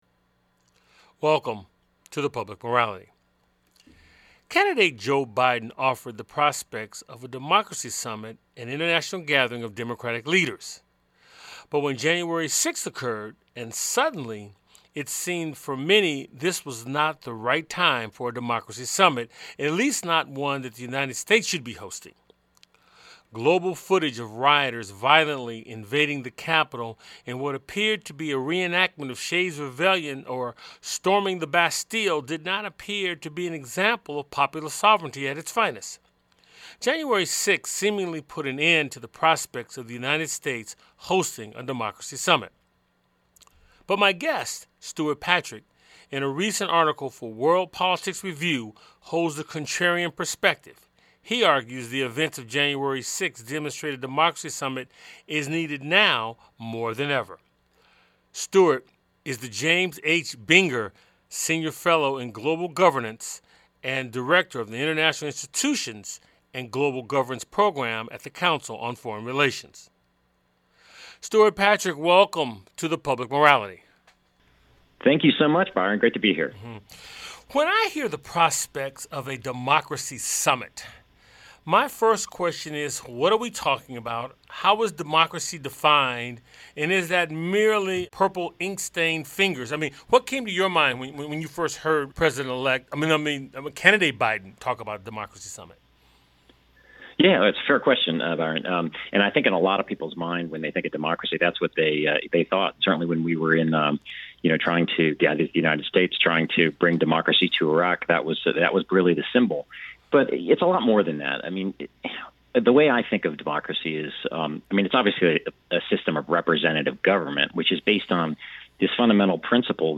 The show airs on 90.5FM WSNC and through our Website streaming Tuesdays at 7:00p.